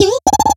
Cri de Charpenti dans Pokémon X et Y.